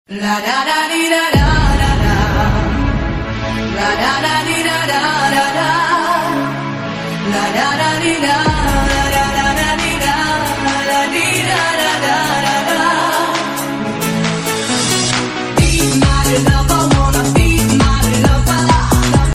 con doppio subwoofer audiosystem ass12